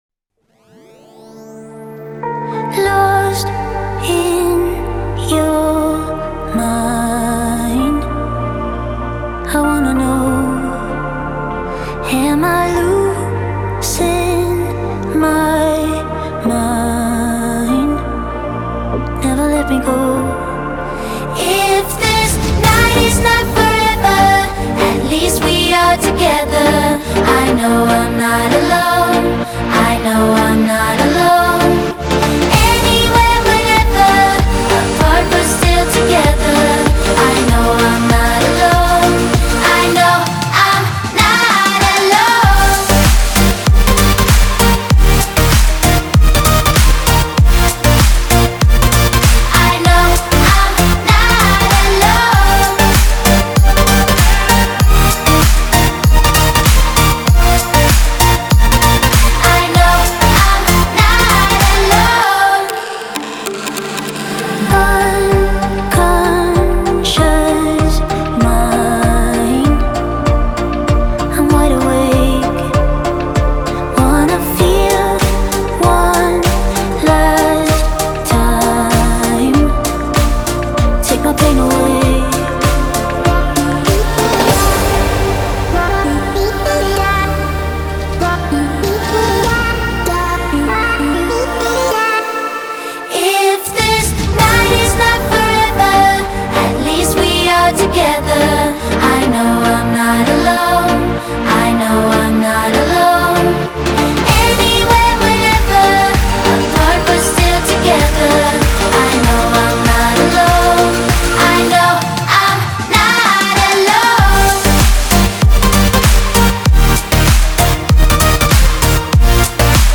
electro-pop
tranquil vocals